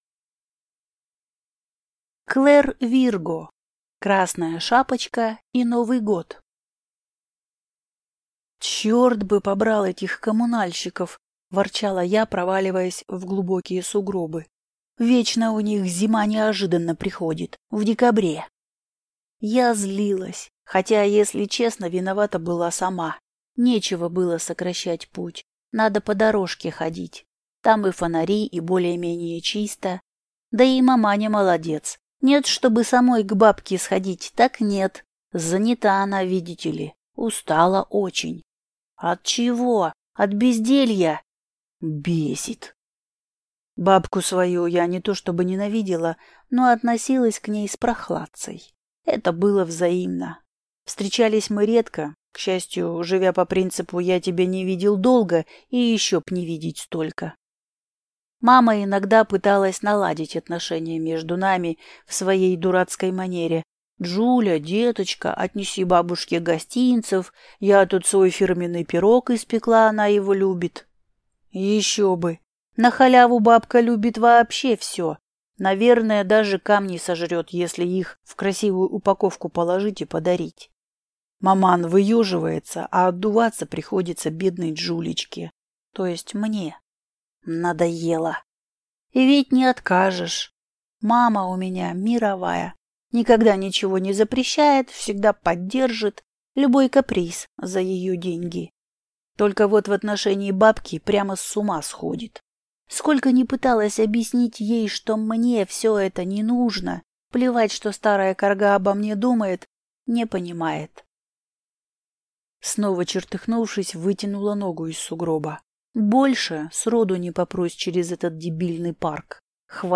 Аудиокнига Красная шапочка и Новый год | Библиотека аудиокниг